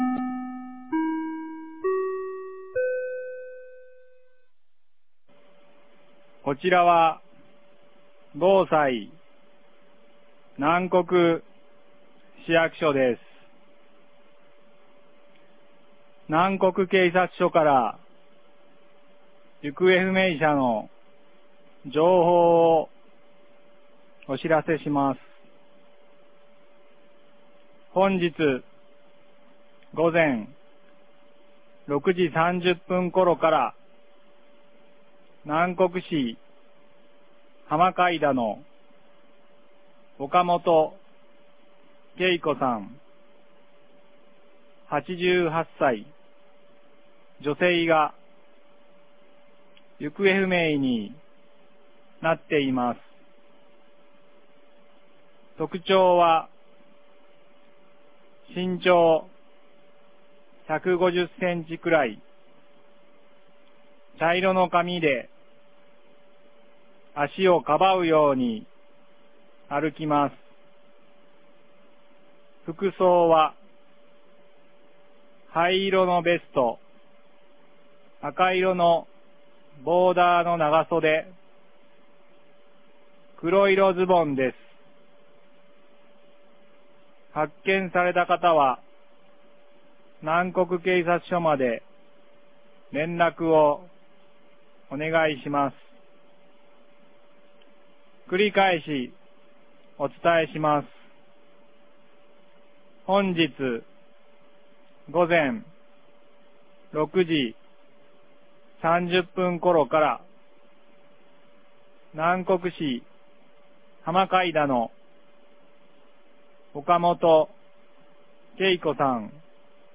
2026年04月01日 11時33分に、南国市より放送がありました。